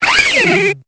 Cri de Dimoret dans Pokémon Épée et Bouclier.